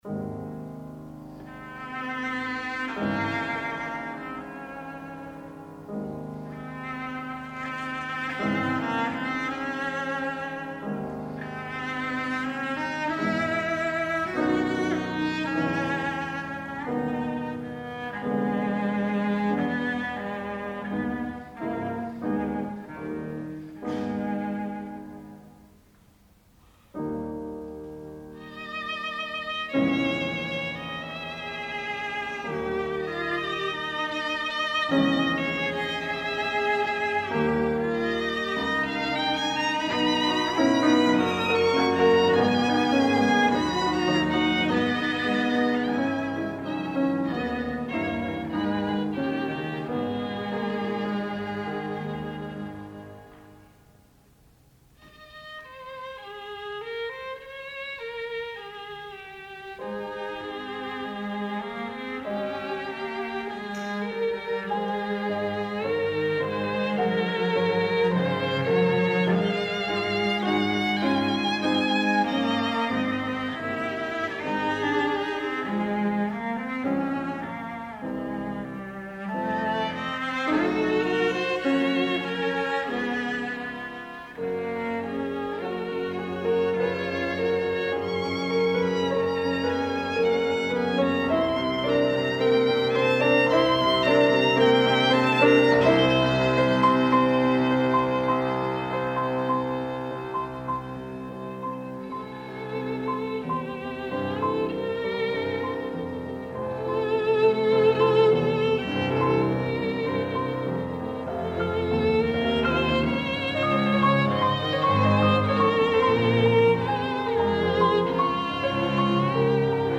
All performances are live unedited concert performances.
Lovely string writing.